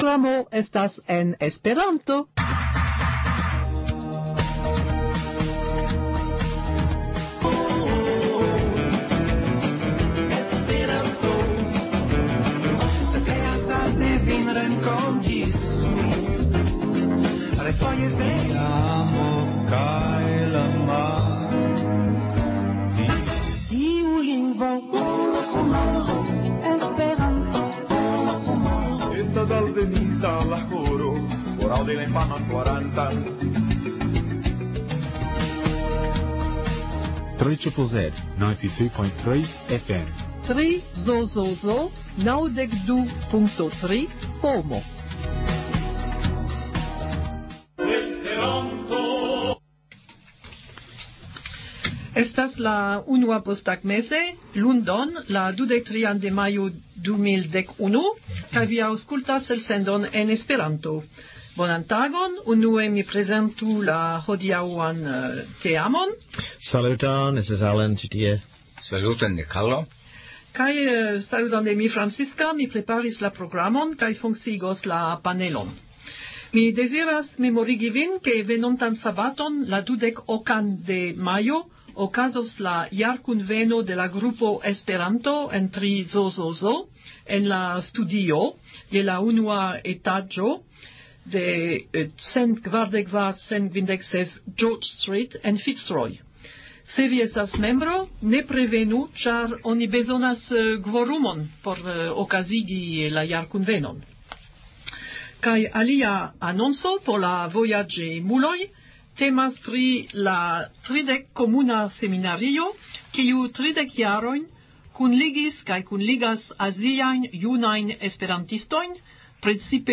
Legado